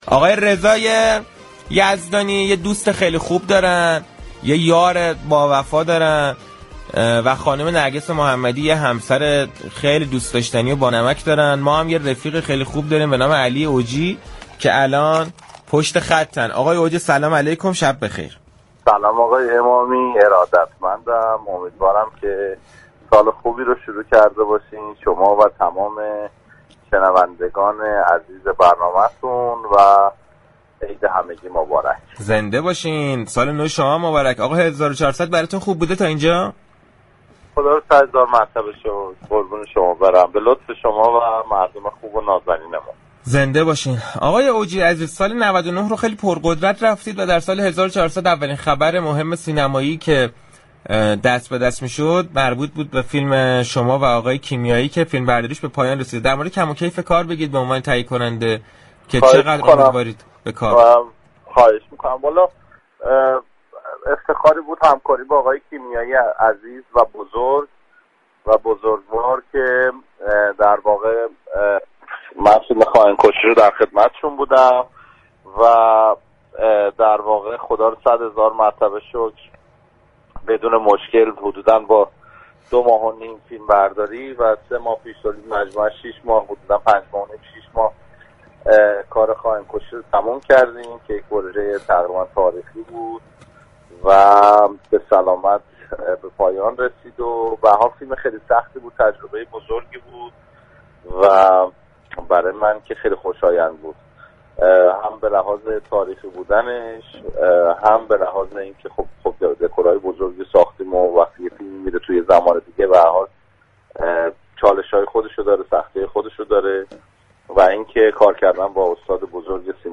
علی اوجی در گفتگوی تلفنی با برنامه صحنه 16 فروردین درباره كم و كیف همكاری اش با مسعود كیمیایی در فیلم سینمایی خائن‌كُشی